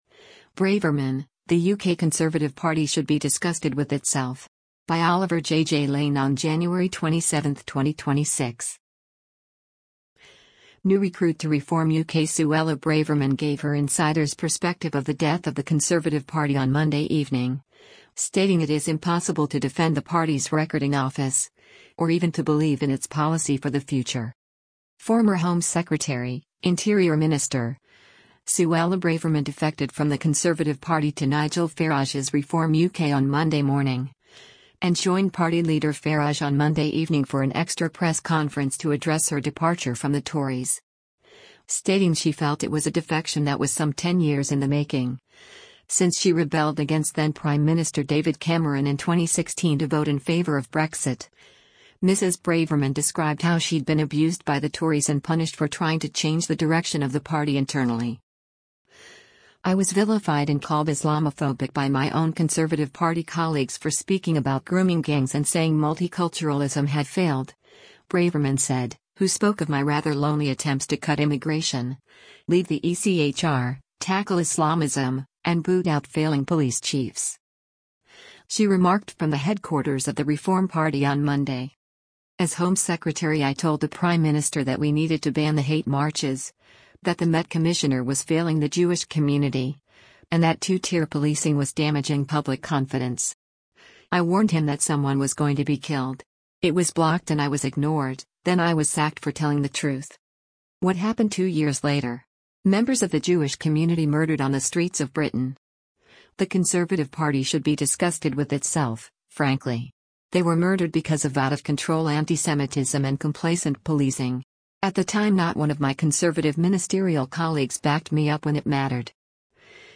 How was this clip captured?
She remarked from the headquarters of the Reform party on Monday: